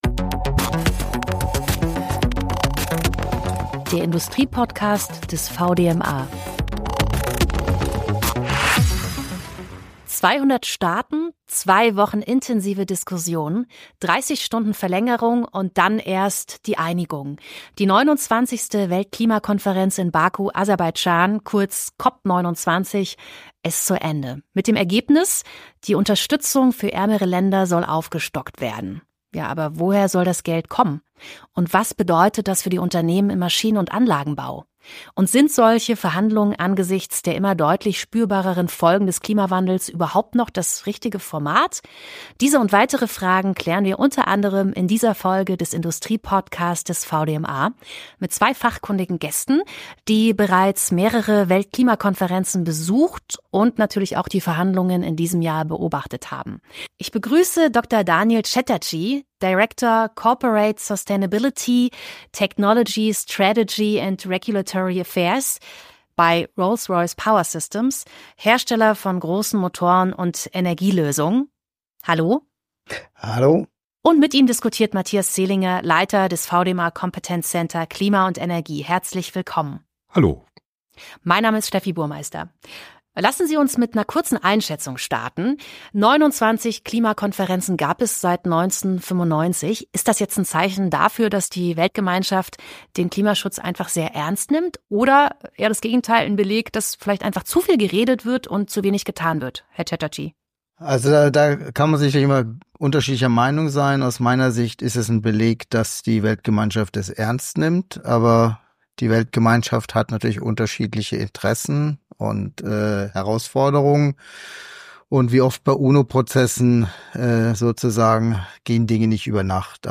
Dazu sprechen wir mit Expertinnen und Experten aus Forschung, Wissenschaft und Unternehmen.